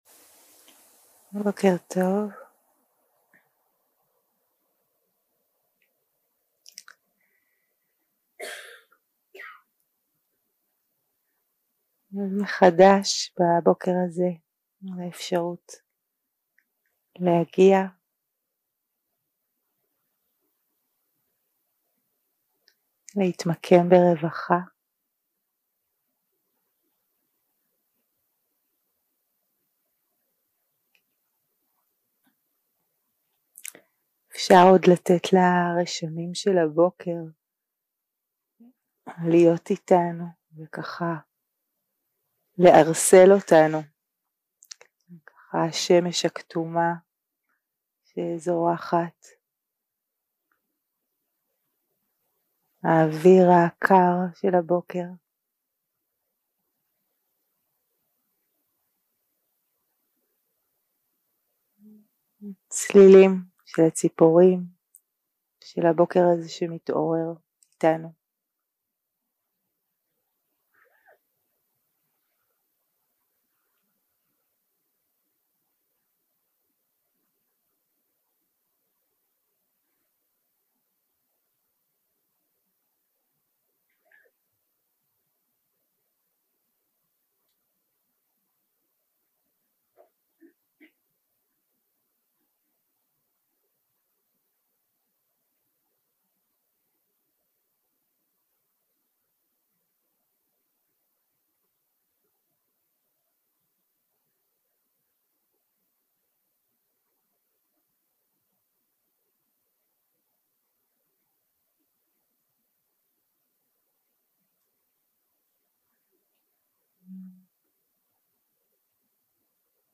יום 2 - הקלטה 1 - בוקר - מדיטציה מונחית
Dharma type: Guided meditation